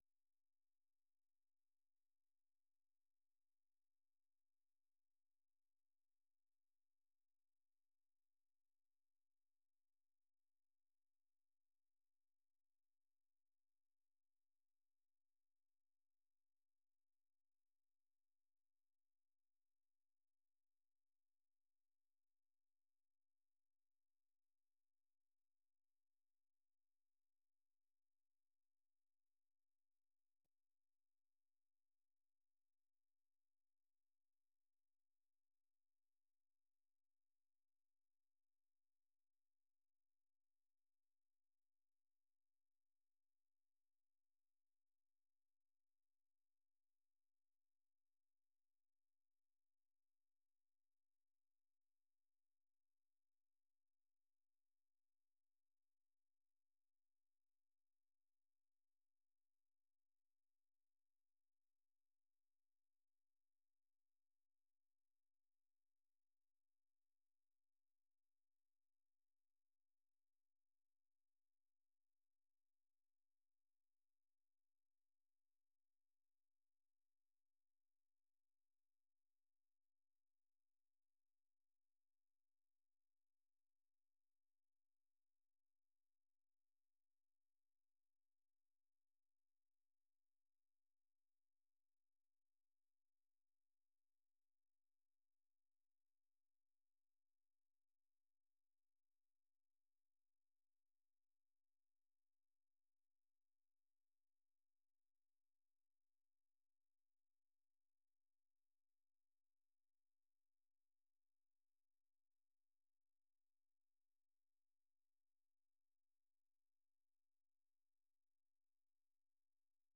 VOA 한국어 방송의 월요일 오전 프로그램 2부입니다.